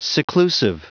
Prononciation du mot seclusive en anglais (fichier audio)
Prononciation du mot : seclusive